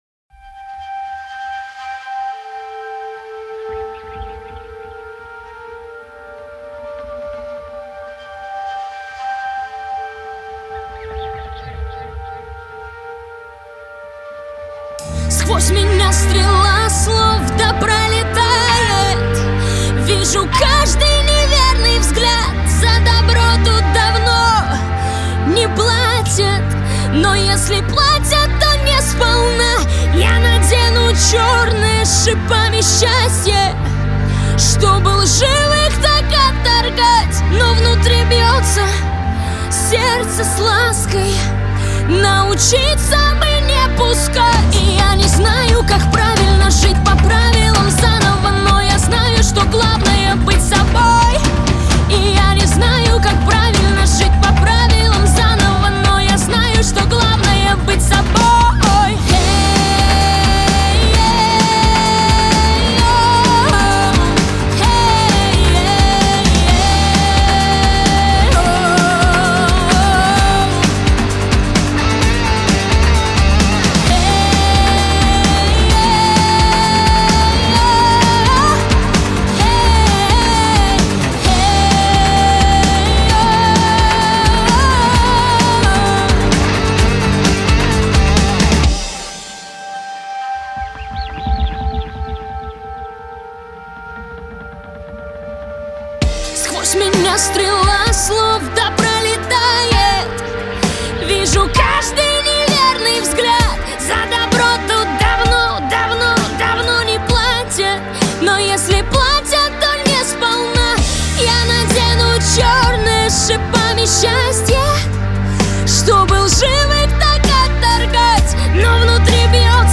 Жанр: Rusrock